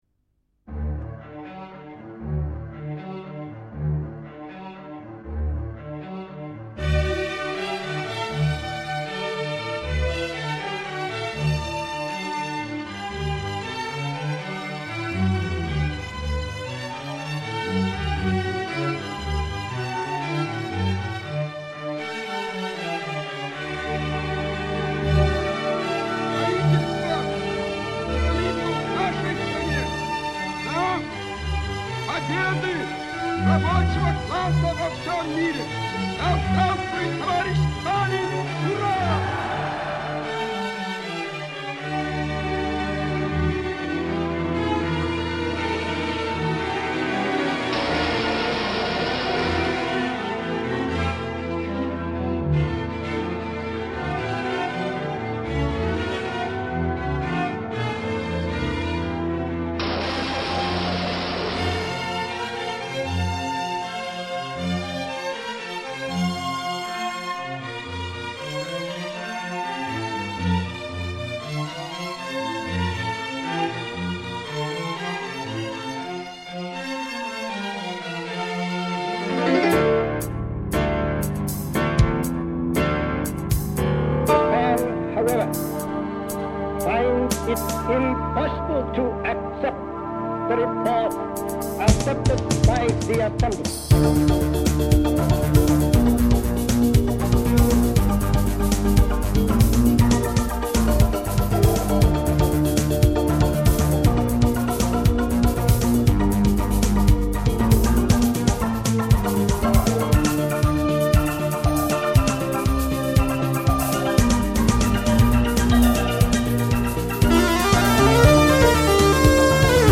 Real Techno-Pop.